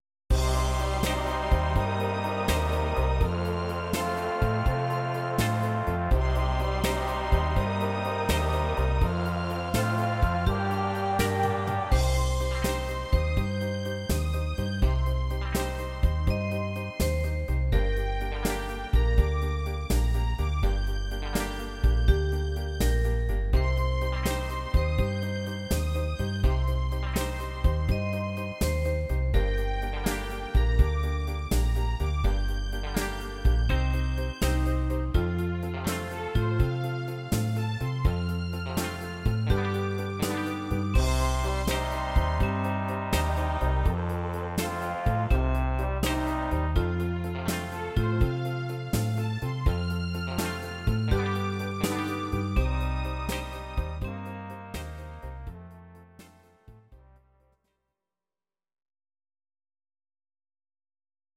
Audio Recordings based on Midi-files
Ital/French/Span, 1960s